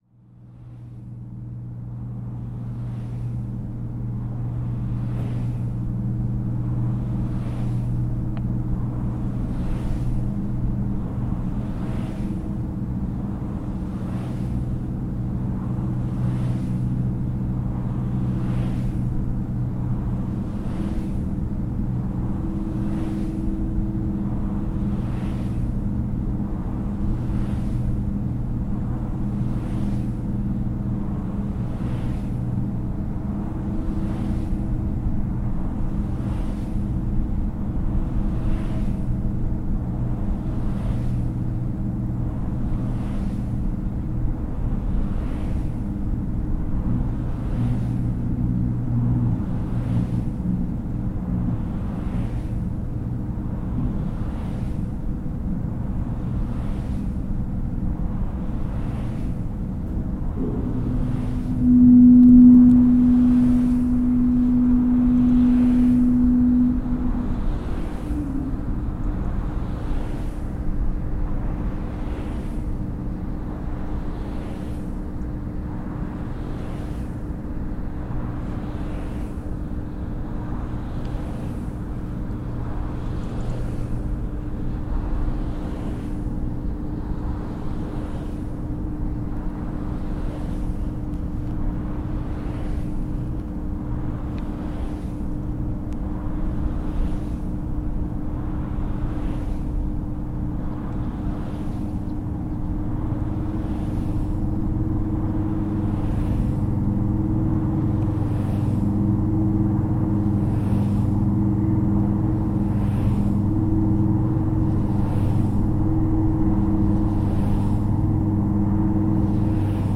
At the heart of a wind turbine
At the wind farm at the Daventry international rail freight terminal, from right at the base of one of the turbines - some fascinating sounds.